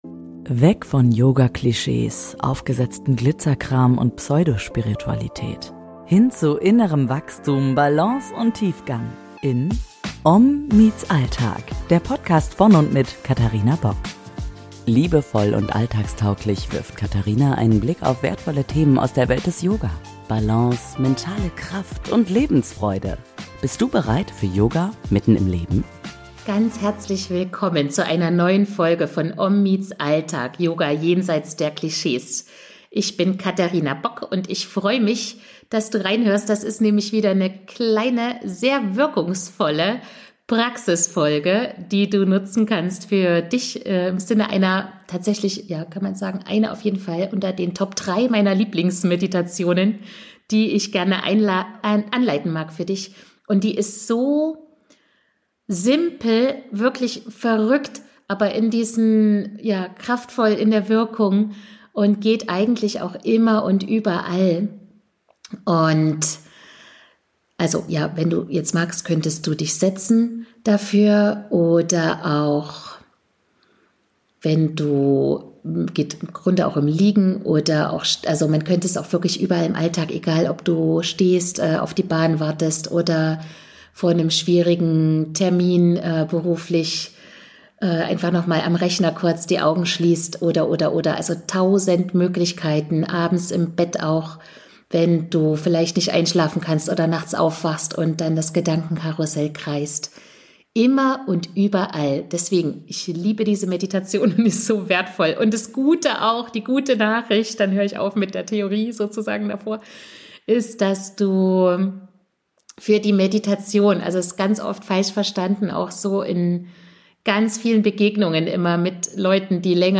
Lass-los-Meditation: Simpel und wirkungsvoll in jeder Lebenslage ~ OM MEETS ALLTAG Podcast